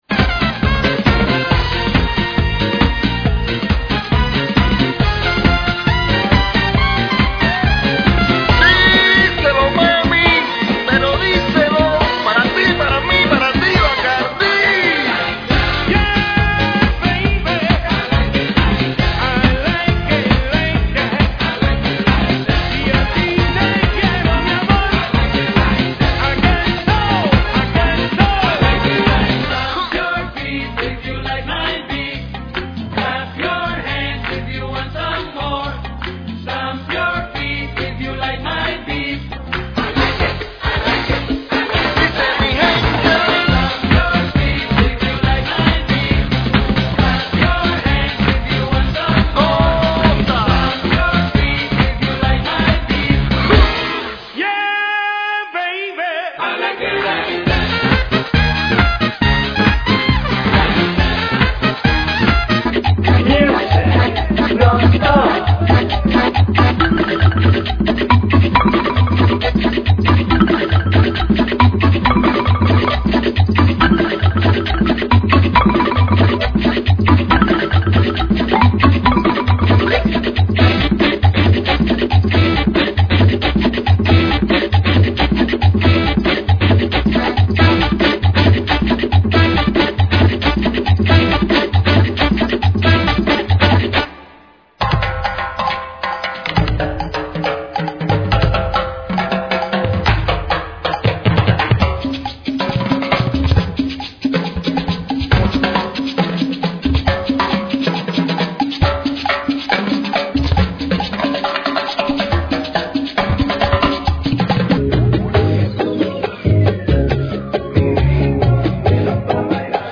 GENERO: LATINO URBANO
AEROBICS (STEP-HILOW)